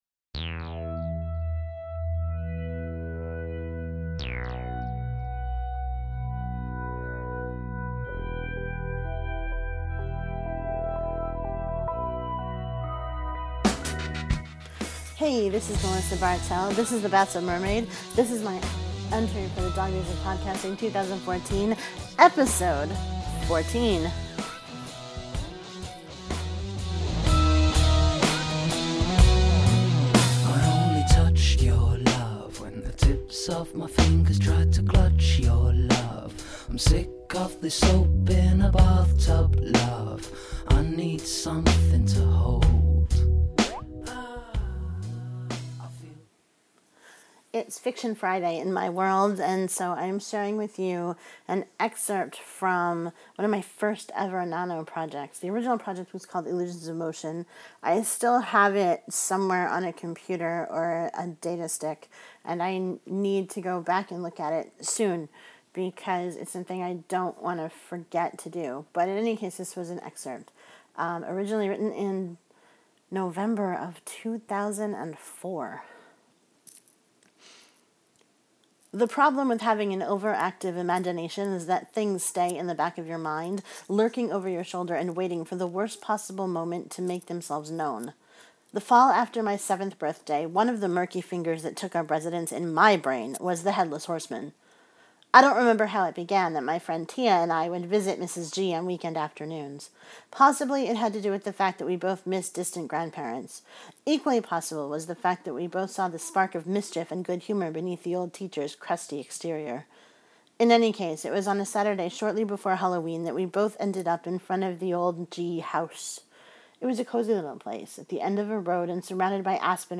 Music for this episode was provided by Mevio’s Music Alley, a great resource for podsafe music.